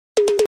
2025-02-16 00:13:03 Gênero: Arrocha Views